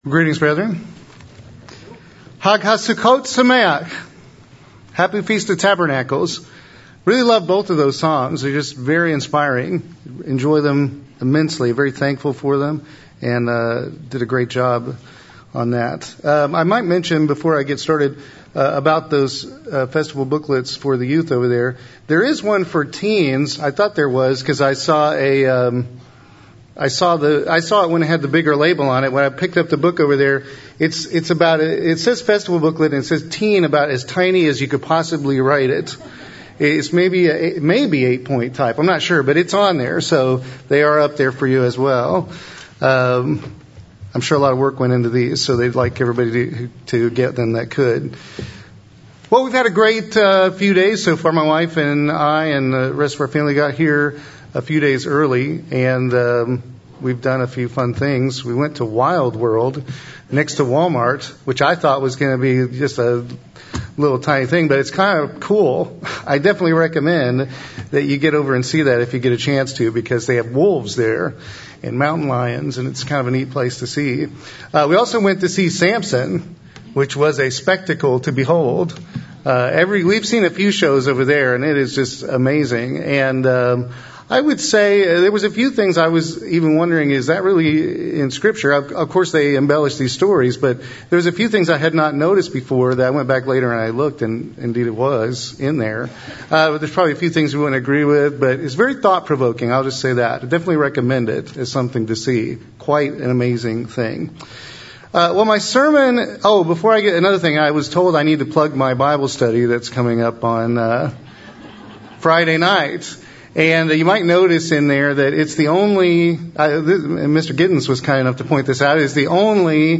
This sermon was given at the Branson, Missouri 2018 Feast site.